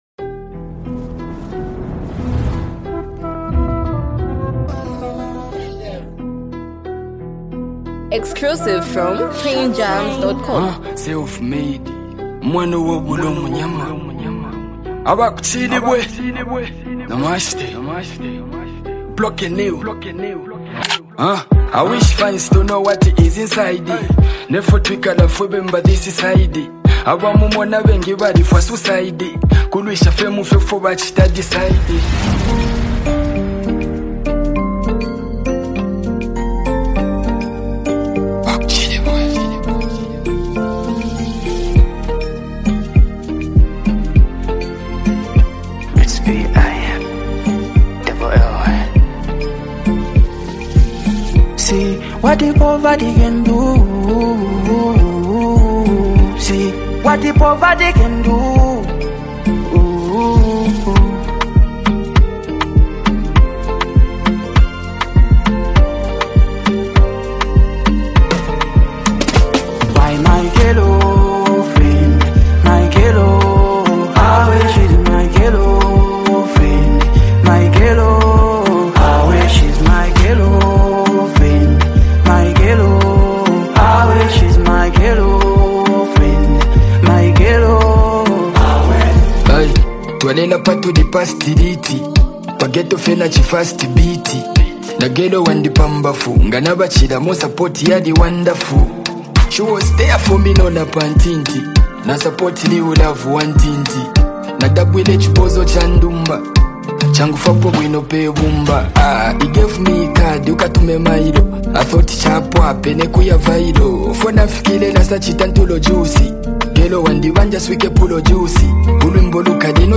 deeply emotional and reflective song